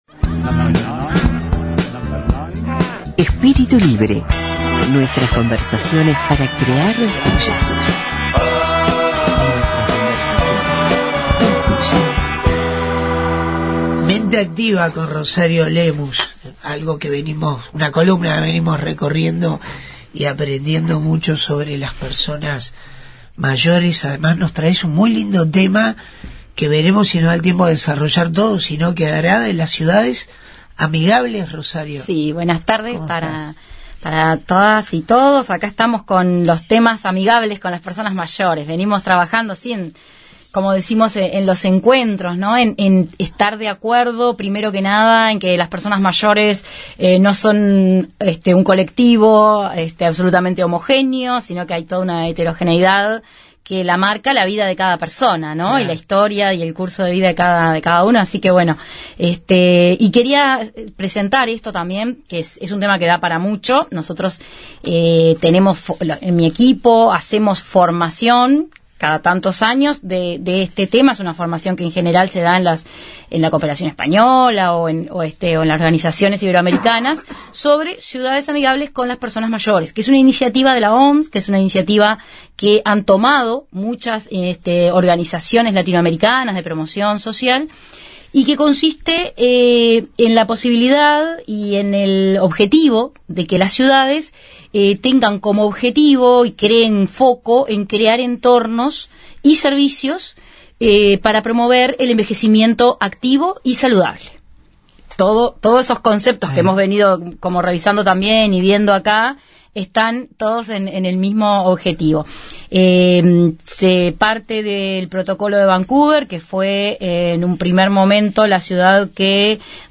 Columna de la psicóloga